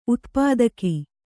♪ utpādana